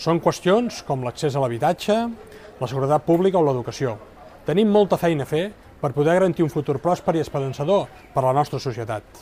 El missatge de Nadal de l’alcalde, amb un record per als afectats per la dana
En aquesta ocasió, es va adreçar a la ciutadania des del balcó de l’edifici de la biblioteca municipal, a l’epicentre d’una Plaça de l’Ajuntament il·luminada per les festes.